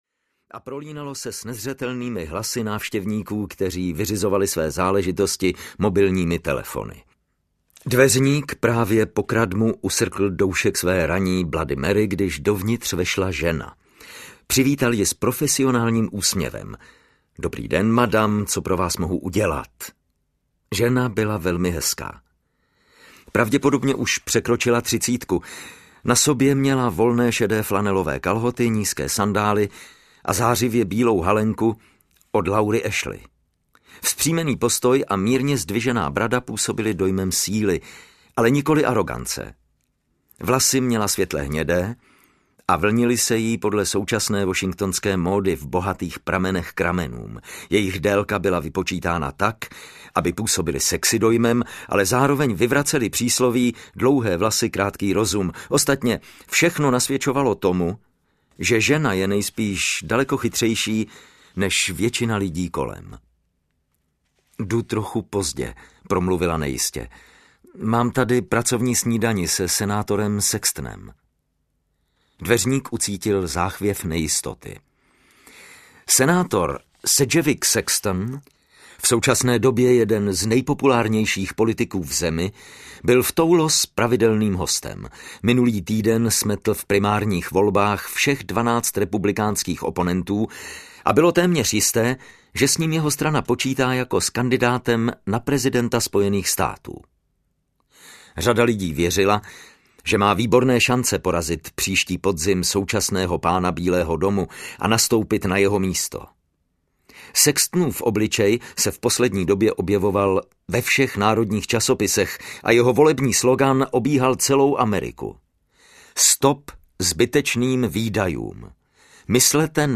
Pavučina lží audiokniha
Ukázka z knihy